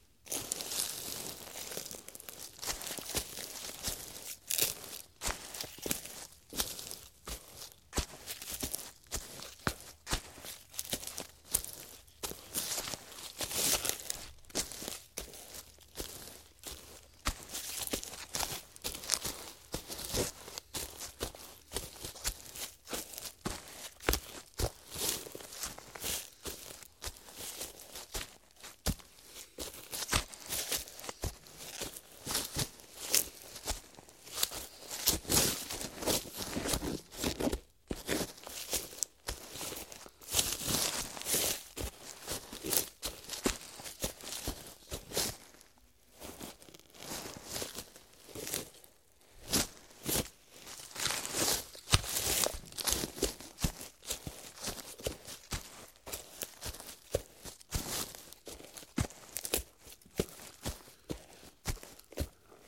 近身搏击 " 脚踏实地的女装平底鞋森林地板
描述：走在叶茂盛森林地板上的妇女的礼服舱内甲的脚步foley。 这种声音是在受控条件下在一个foley工作室录制的。
用Blue Bluebird麦克风录制到Tascam DR40上。
Tag: 脚步 工作室 FX SFX soundeffects soundfx 声音 拟音